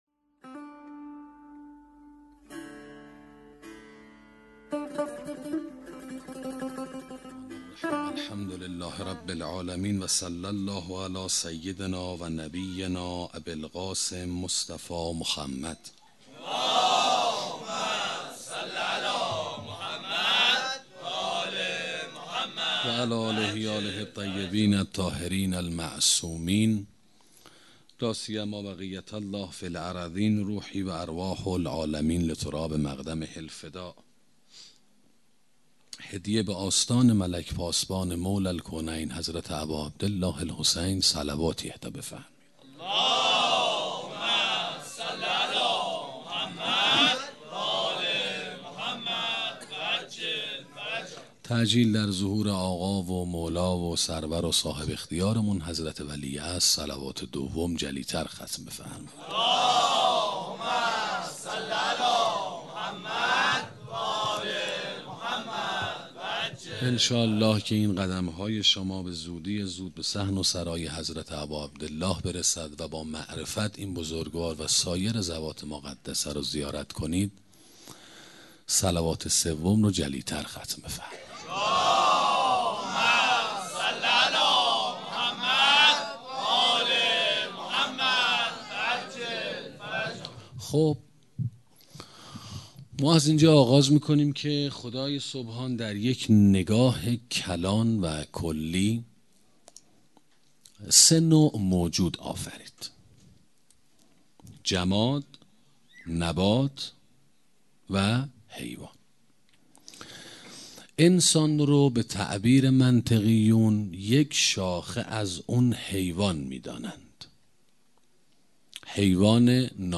سخنرانی انتخاب و اختیار 1 - موسسه مودت